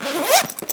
action_open_backpack_3.ogg